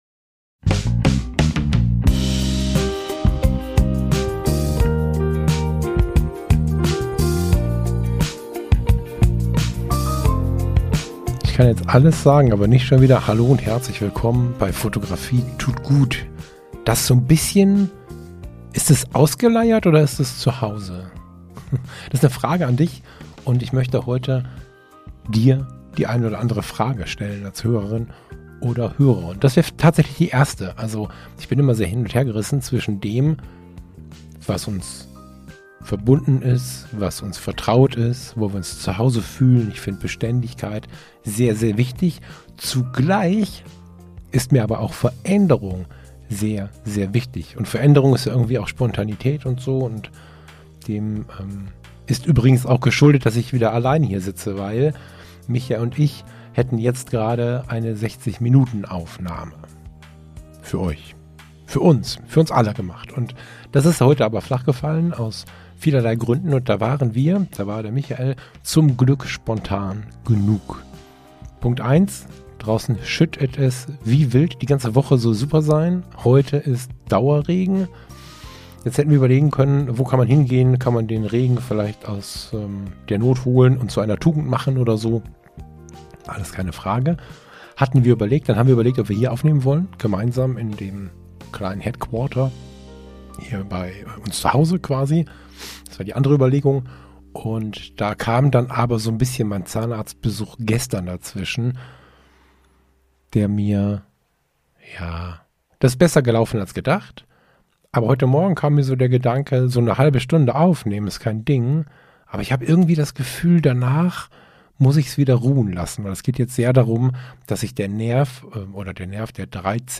Diese Solofolge beschäftigt sich mit meiner und unserer Entwicklung in der Podcast-Welt und ich freue mich auf Deine Gedanken dazu!